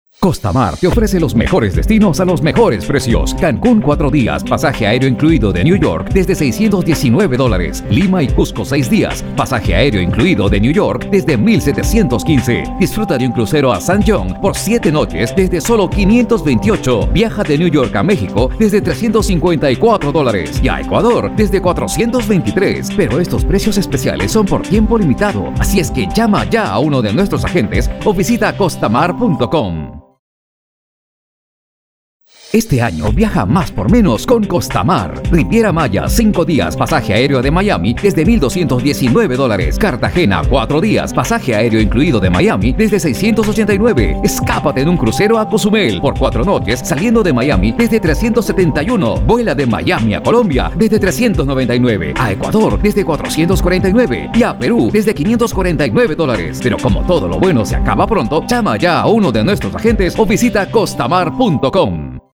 Promo